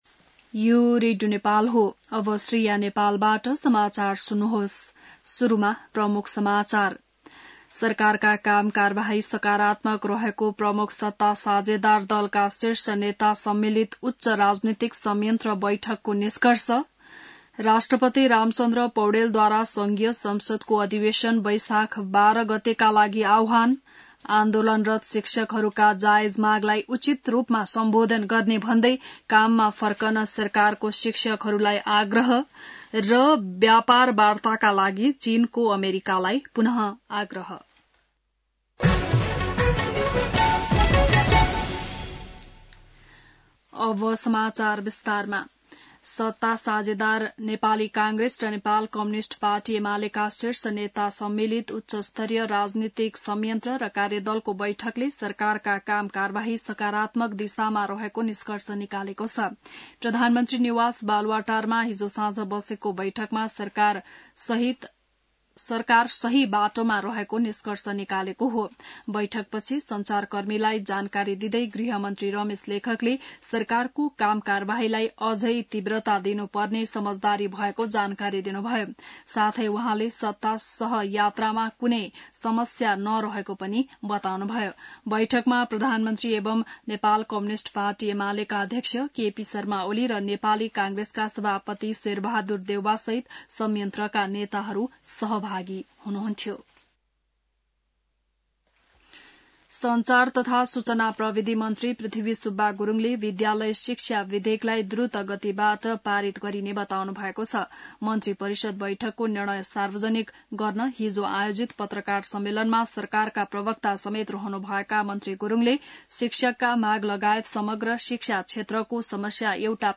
बिहान ९ बजेको नेपाली समाचार : ४ वैशाख , २०८२